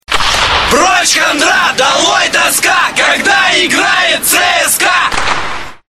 Футбольные кричалки